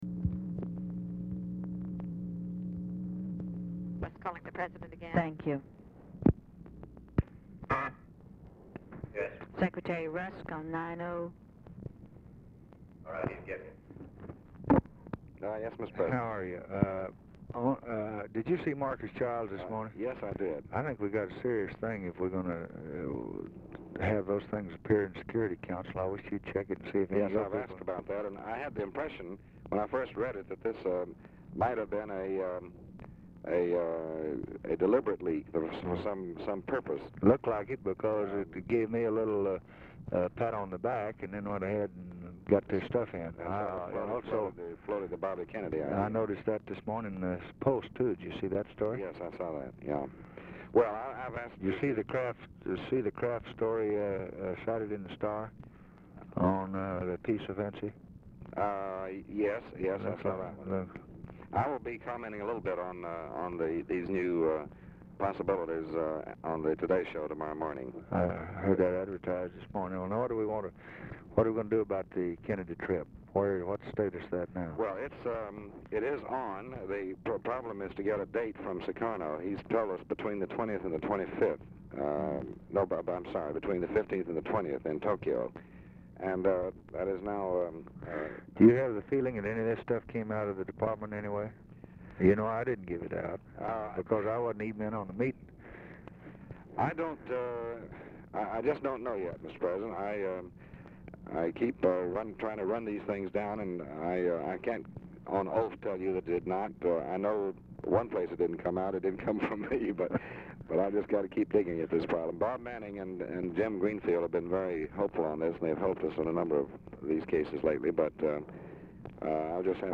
Telephone conversation # 1341, sound recording, LBJ and DEAN RUSK, 1/13/1964, 12:47PM
Oval Office or unknown location
Telephone conversation
Dictation belt